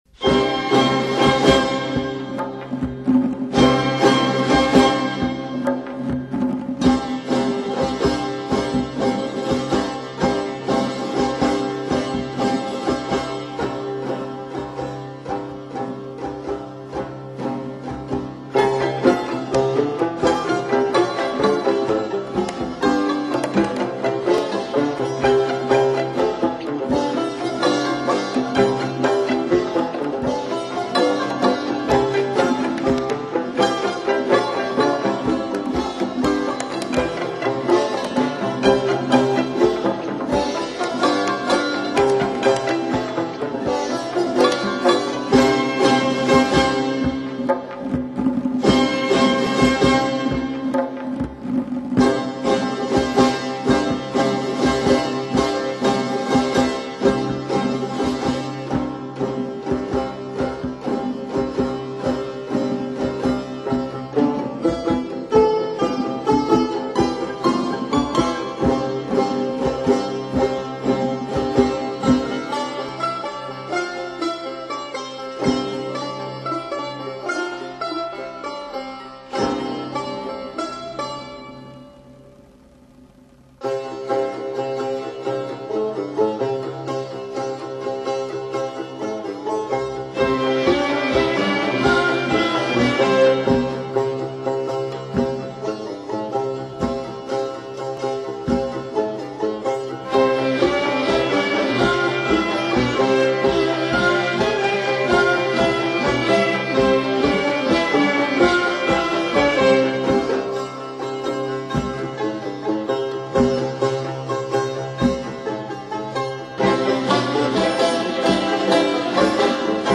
این اثر در ریتم لَنگ ۷/۸ ساخته شده است.